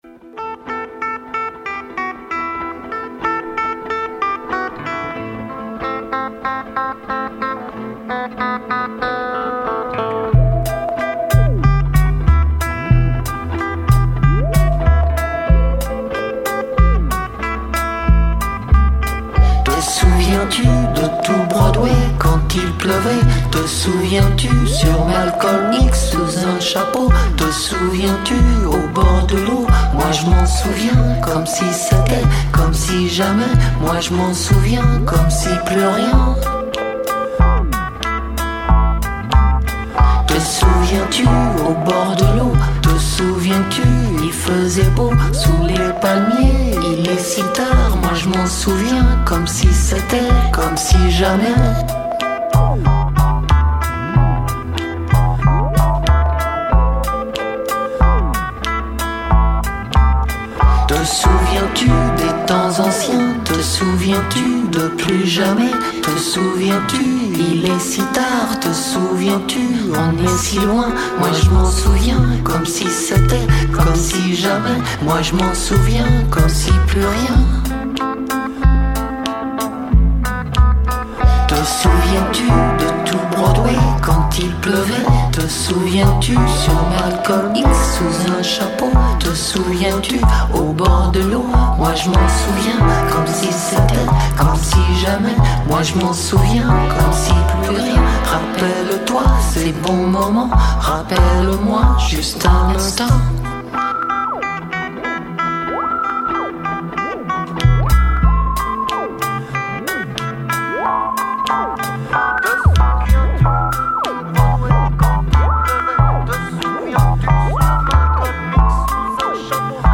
world